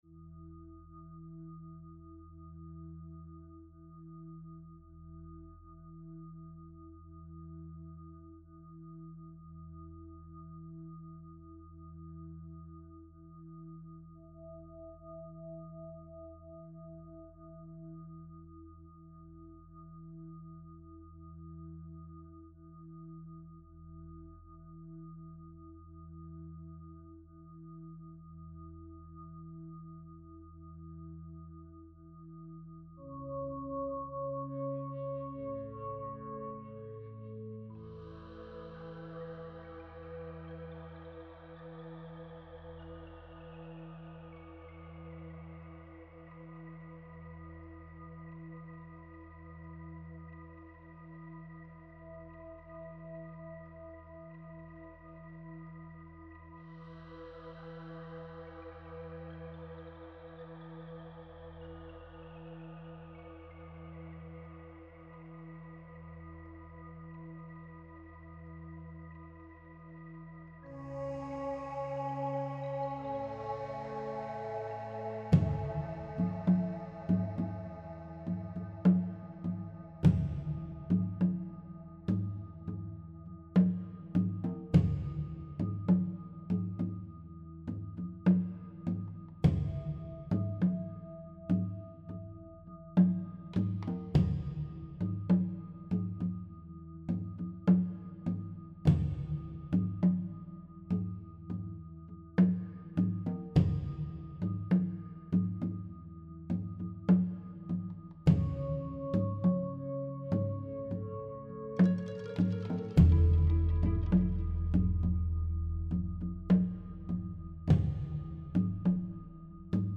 without the animal sounds